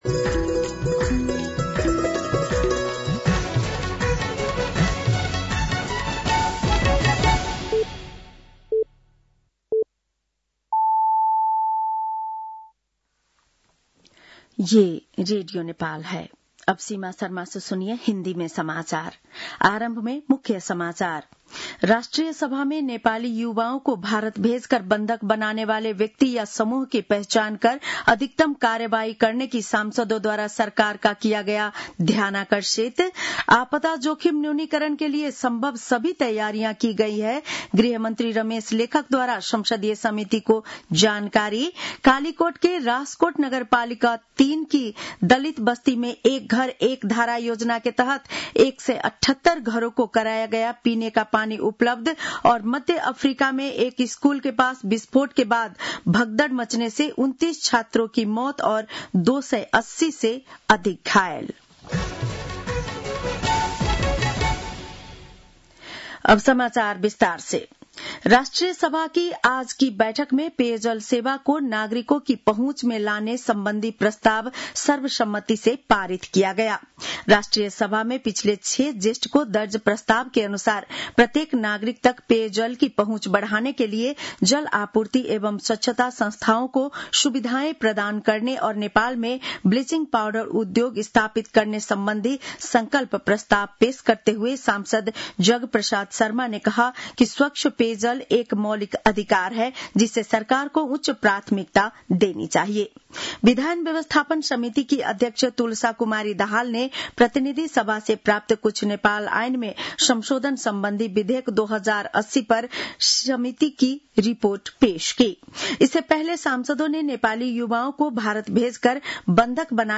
बेलुकी १० बजेको हिन्दी समाचार : १२ असार , २०८२
10-PM-Hindi-NEWS-3-12.mp3